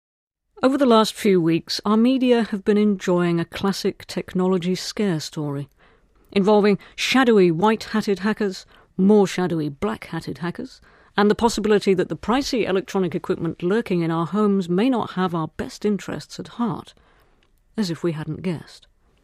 【英音模仿秀】你所不知的僵尸网络 听力文件下载—在线英语听力室